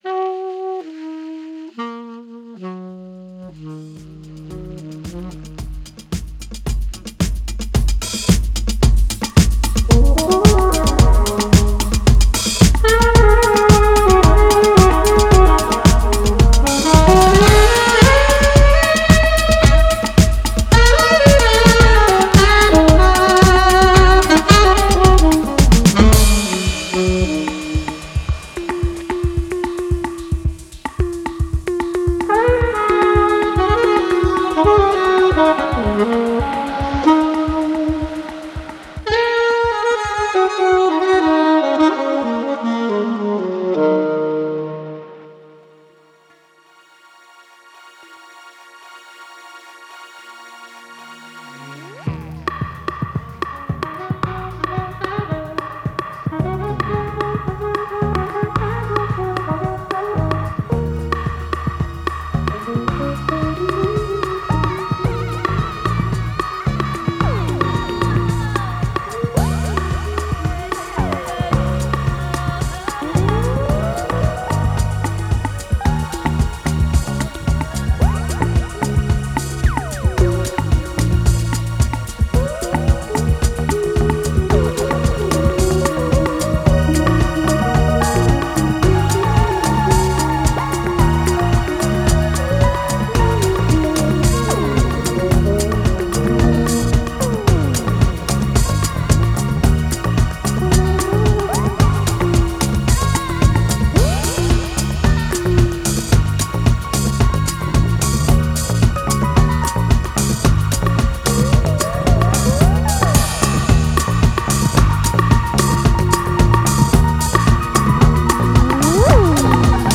s'aventurant parfois aux frontières du dub ou du disco.